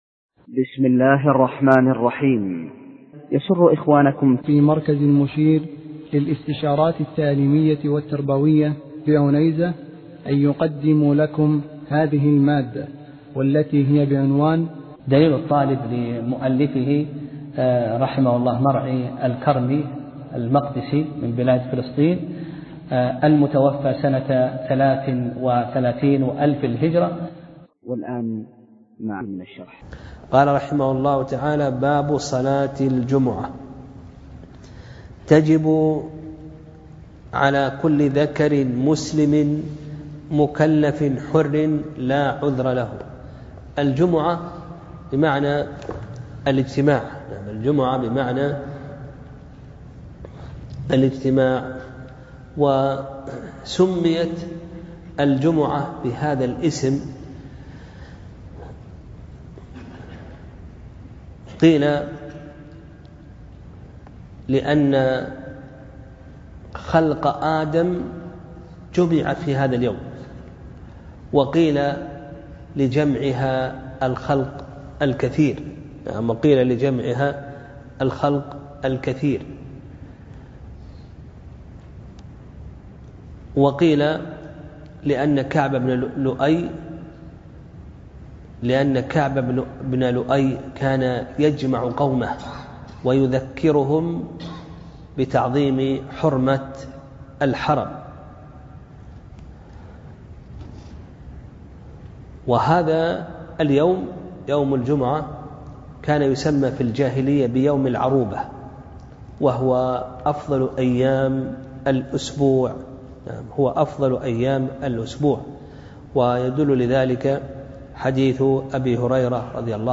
درس (19) : باب صلاة الجمعة (1)